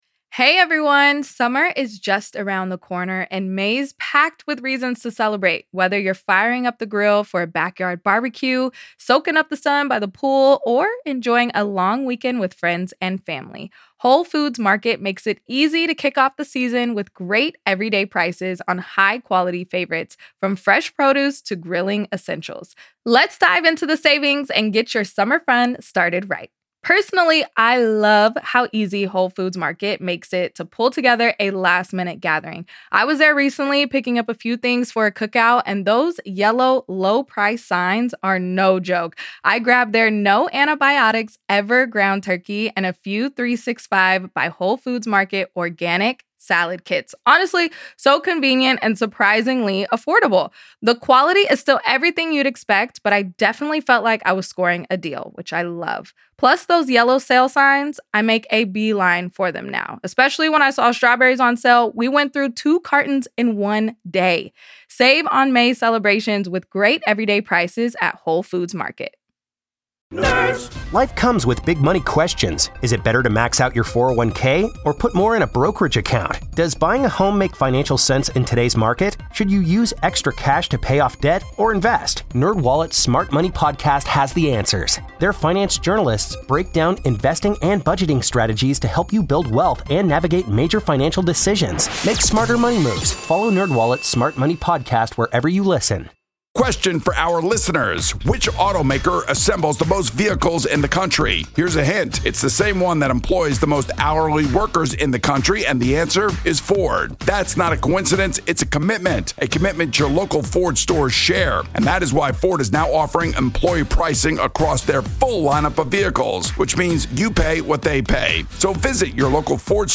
Doing a show outside with the sun shining on you, Huberman would be very proud, but it just makes you feel better the rest of the day.